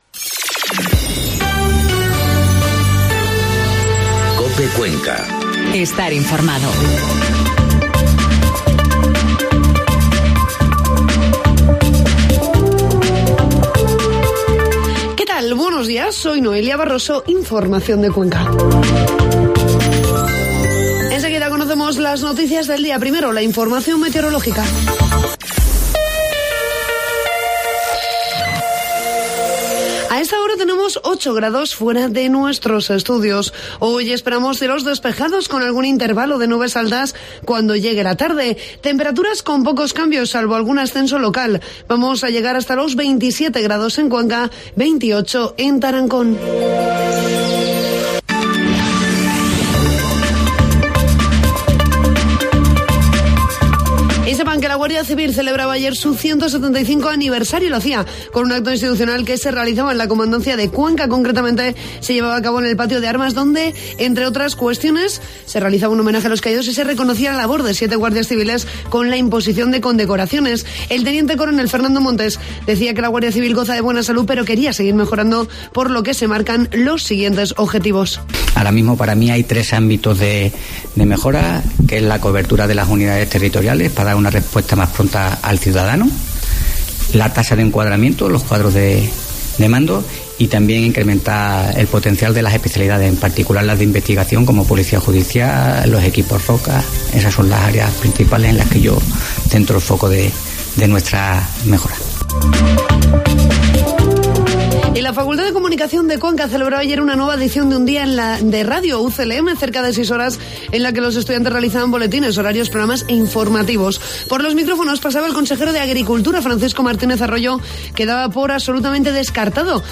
Informativo matinal COPE Cuenca 15 de mayo